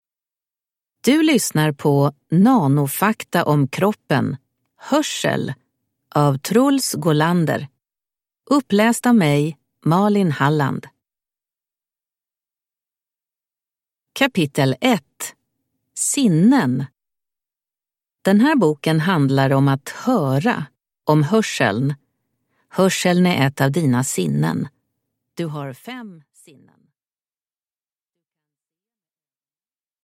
Nanofakta om kroppen. Hörsel – Ljudbok